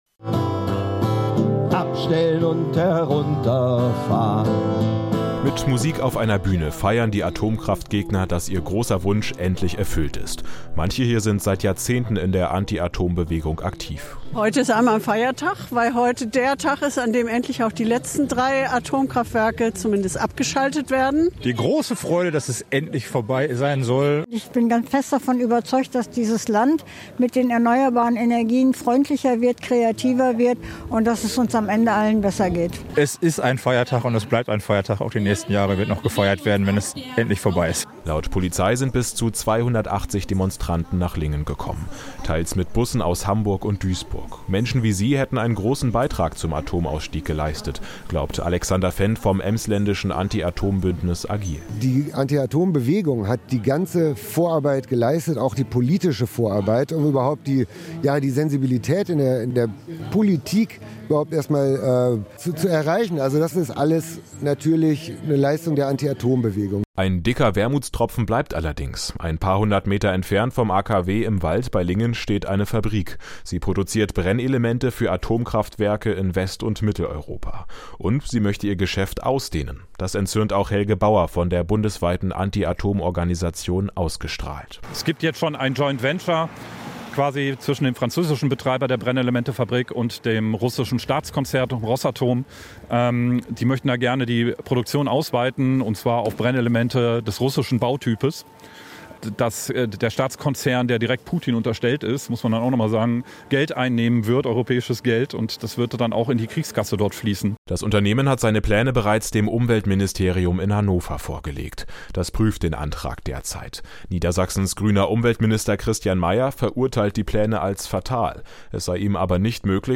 Stimmungsbild vom Atomausstieg AKW Lingen